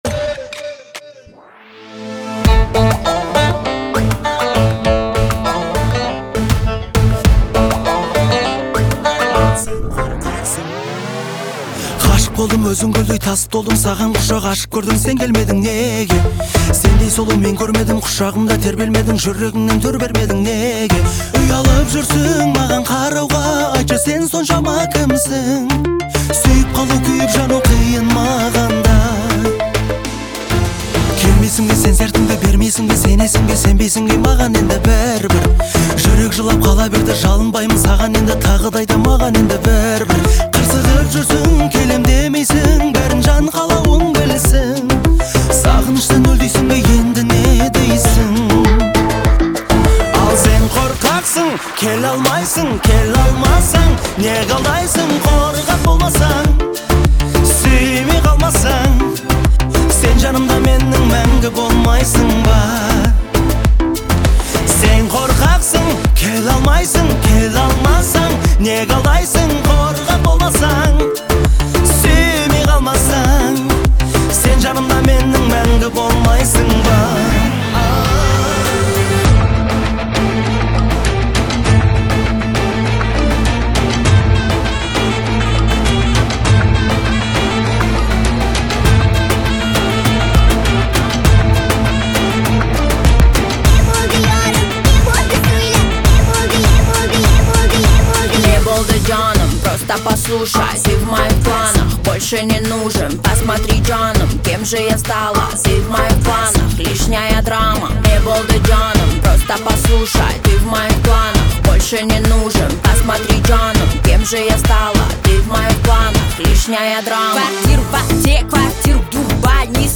Казахская музыка